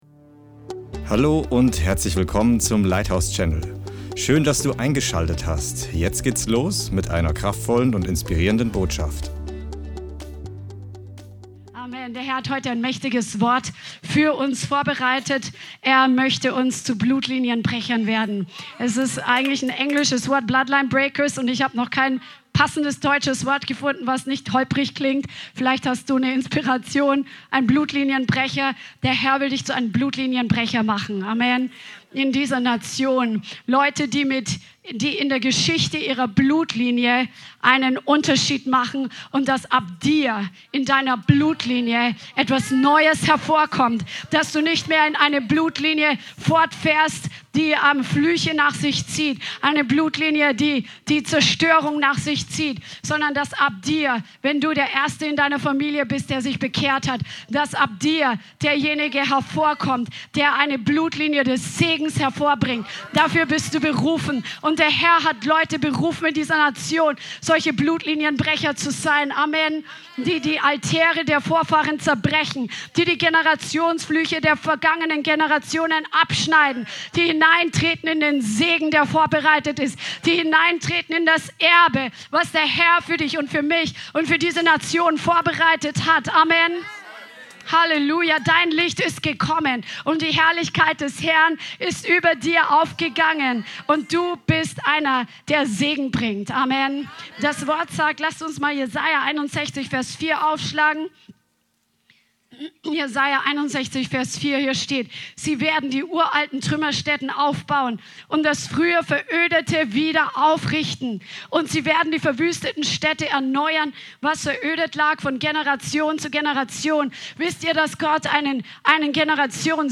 Diese Predigt rüstet dich zu, damit du einen Unterschied in deiner Generation und Blutlinie machen kannst. Sie gibt dir Schlüssel zu deiner persönlichen Freisetzung und die deiner Blutlinie an die Hand....